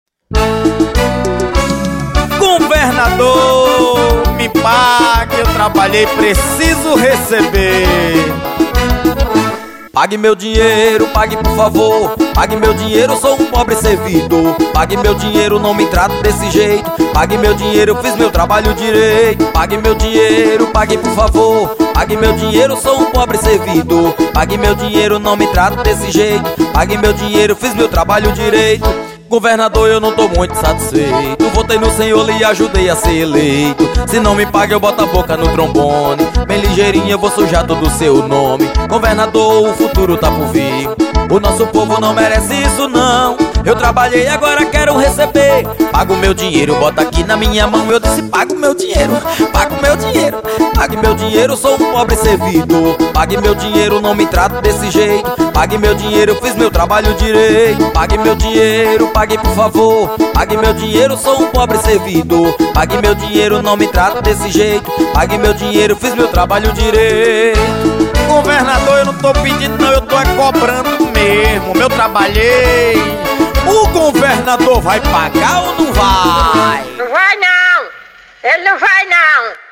O cantor/compositor